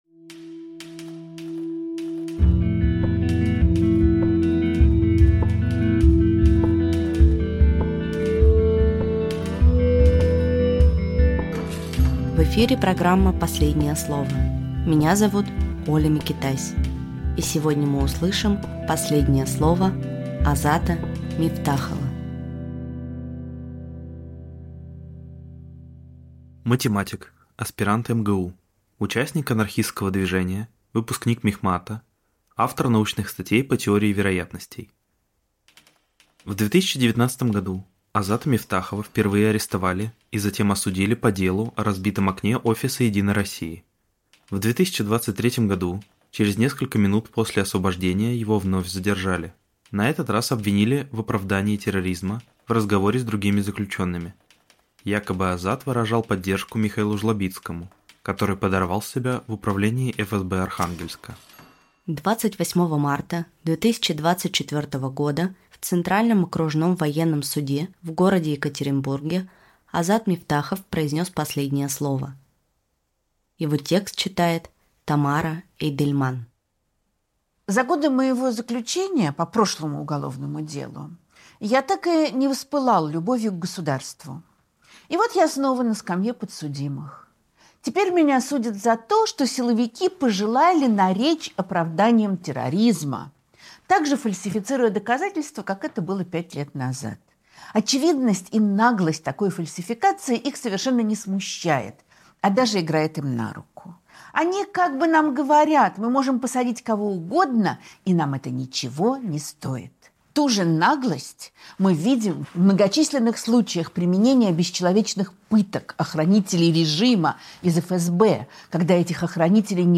Читает Тамара Эйдельман